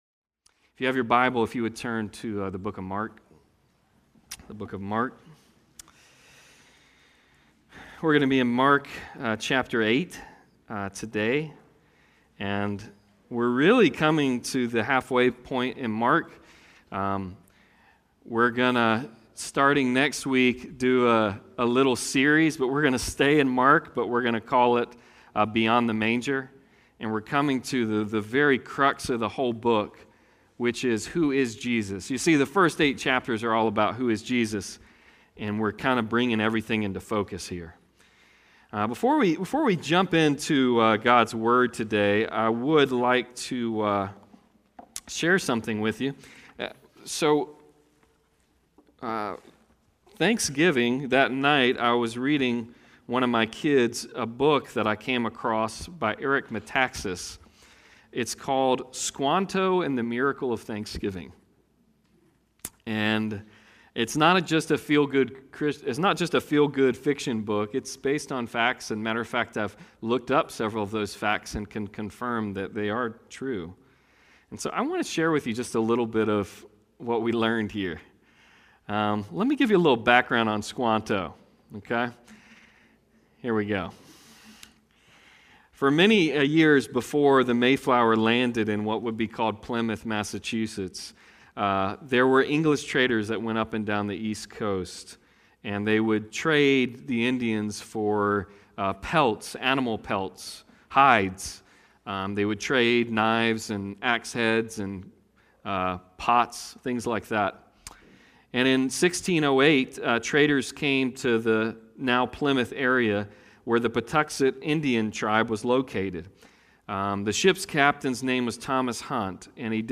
Passage: Mark 8:1-21 Service Type: Sunday Service